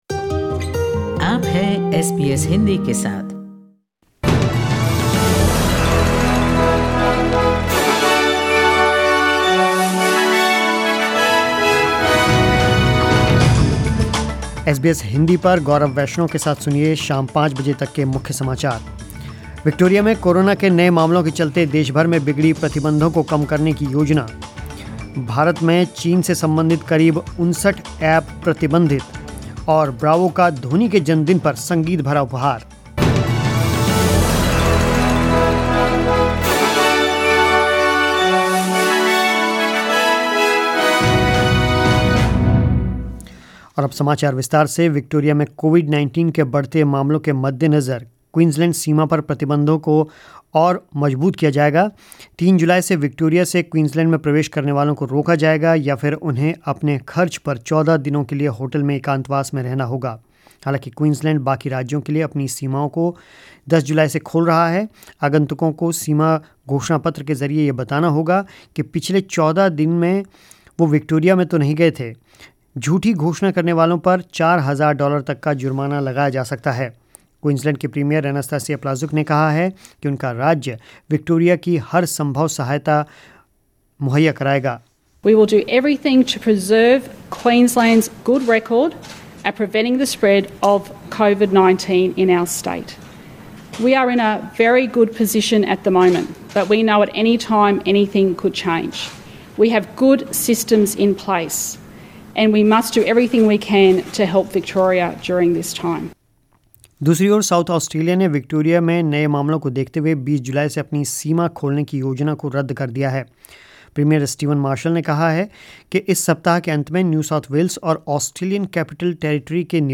News In Hindi 30 June 2020